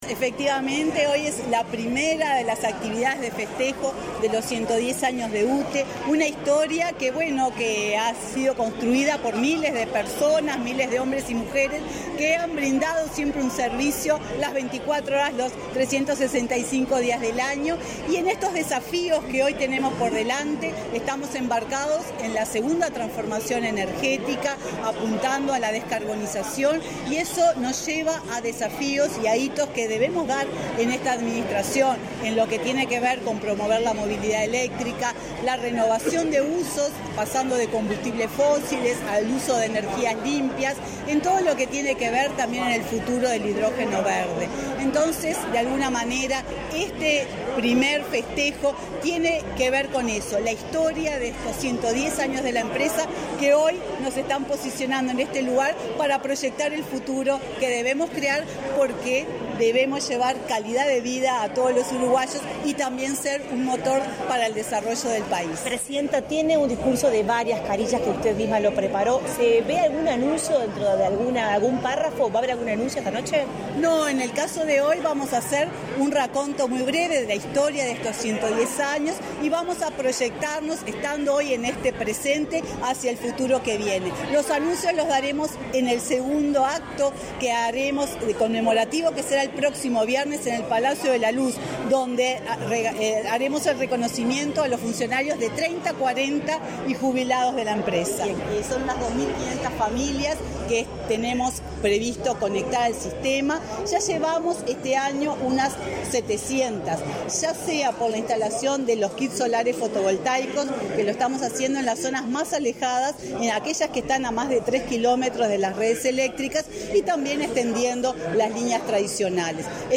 Declaraciones a la prensa de la presidenta de UTE, Silvia Emladi
La UTE festejó sus 110 años de creación este 12 de octubre. Previo al evento, la titular del ente, Silvia Emaldi, realizó declaraciones a la prensa.